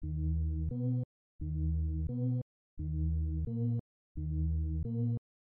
低音说唱
Tag: 87 bpm Hip Hop Loops Drum Loops 950.47 KB wav Key : Unknown FL Studio